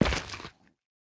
Minecraft Version Minecraft Version latest Latest Release | Latest Snapshot latest / assets / minecraft / sounds / block / shroomlight / step1.ogg Compare With Compare With Latest Release | Latest Snapshot